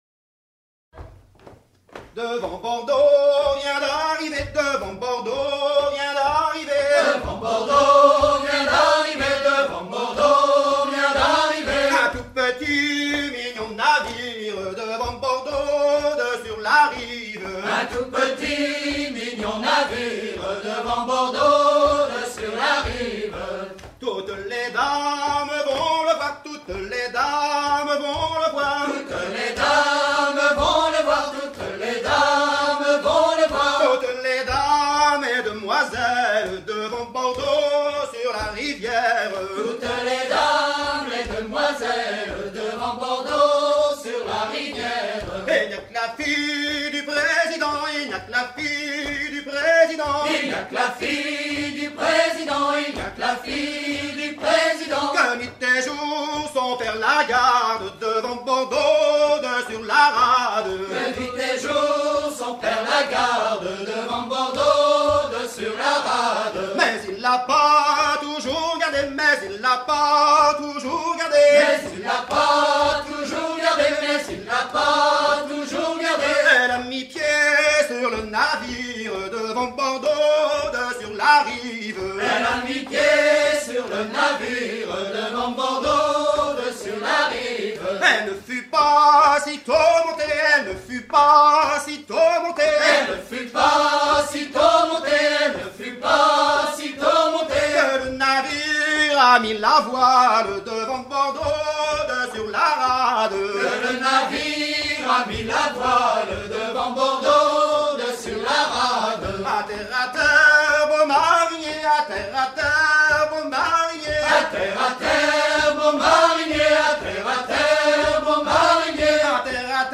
Version menée en demi-rond
danse : ronde : rond de l'Île d'Yeu
Pièce musicale éditée